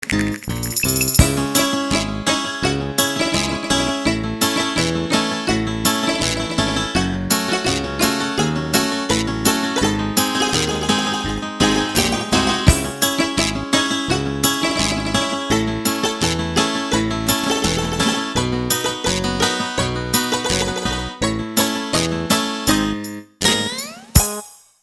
• Качество: 320, Stereo
инструментальные
OST